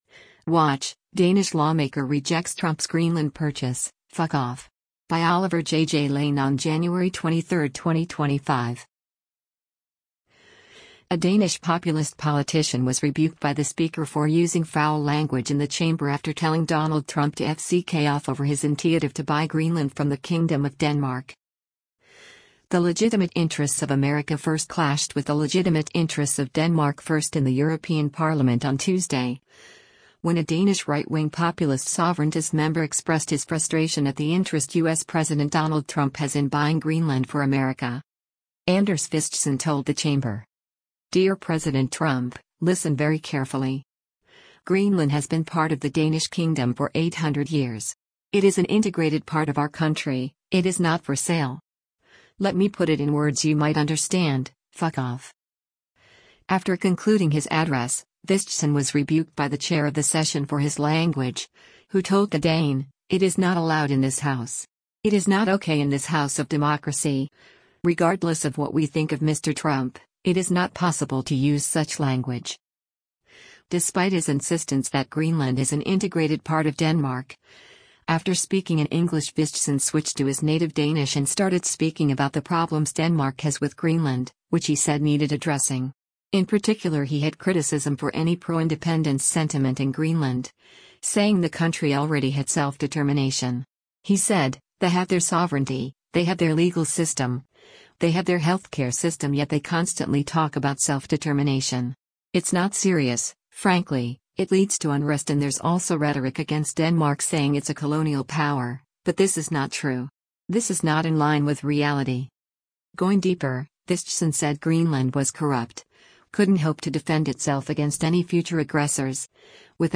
A Danish populist politician was rebuked by the speakerfor using foul language in the chamber after telling Donald Trump to “f*ck off” over his intiative to buy Greenland from the Kingdom of Denmark.
The legitimate interests of America First clashed with the legitimate interests of Denmark First in the European Parliament on Tuesday, when a Danish right-wing populist-sovereigntist Member expressed his frustration at the interest U.S. President Donald Trump has in buying Greenland for America.
After concluding his address, Vistisen was rebuked by the chair of the session for his language, who told the Dane: “It is not allowed in this house… It is not OK in this house of democracy, regardless of what we think of Mr Trump, it is not possible to use such language.”